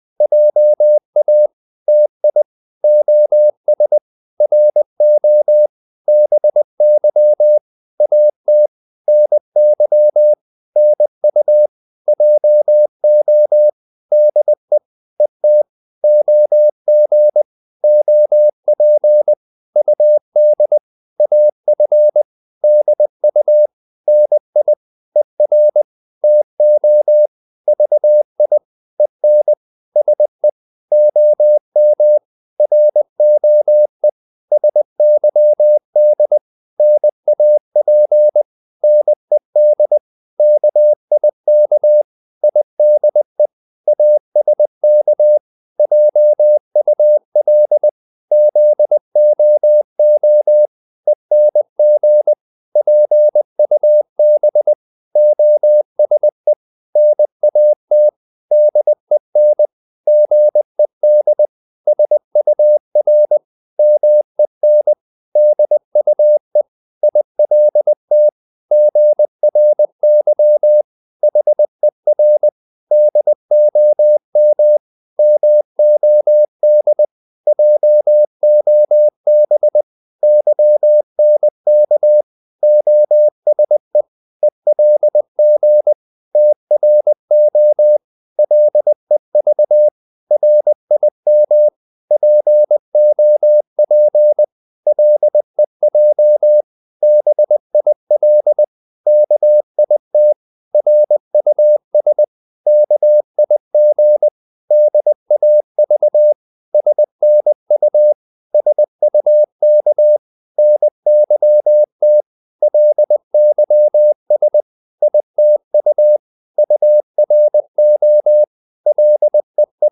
Korte danske ord 20wpm | CW med Gnister
Korte ord DK 20wpm.mp3